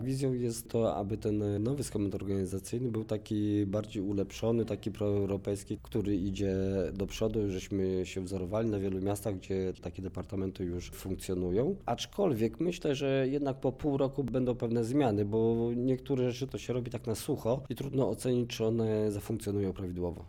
– Ograniczona ilość dyrektorów – dzięki nowej strukturze – ma zmniejszyć wydatki na pobory i przynieść oszczędności w miejskiej kasie – mówi Artur Juszczak, wiceprezydent Chełma.